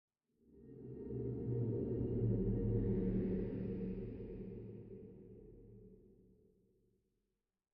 Minecraft Version Minecraft Version 1.21.5 Latest Release | Latest Snapshot 1.21.5 / assets / minecraft / sounds / ambient / nether / crimson_forest / voom2.ogg Compare With Compare With Latest Release | Latest Snapshot